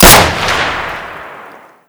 shoot3.ogg